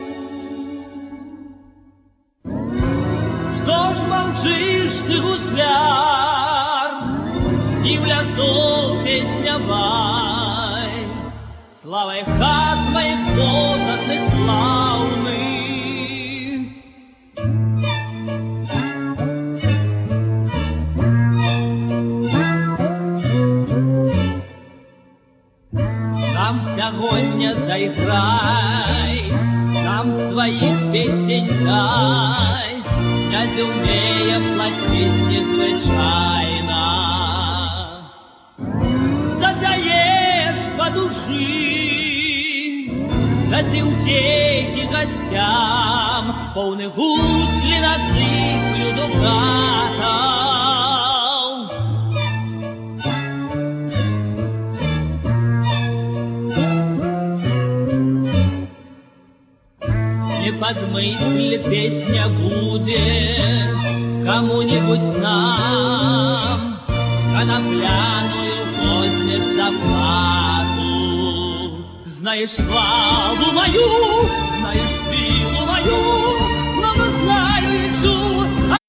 Vocal Instrumental Ensemble